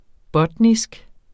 botnisk adjektiv Bøjning -, -e Udtale [ ˈbʌdnisg ] Oprindelse af gammelsvensk butn 'bund' Betydninger vedr.